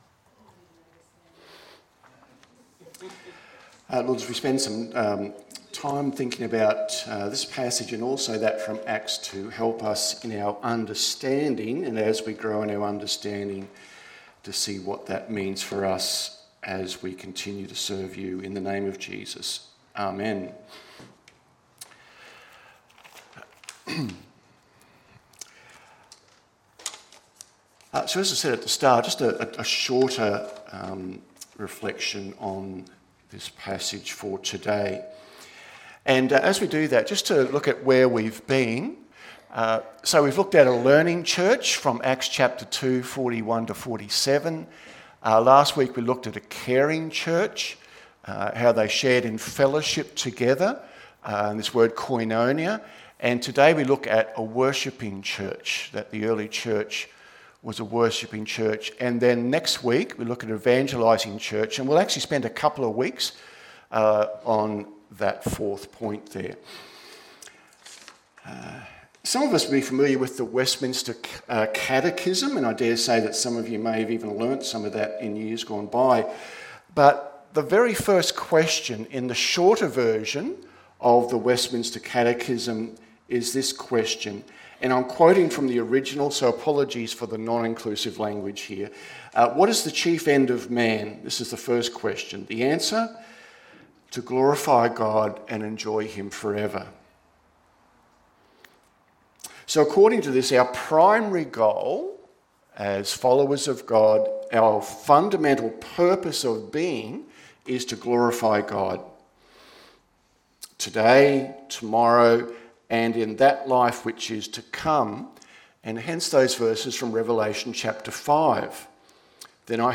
Sermon, audio.mp3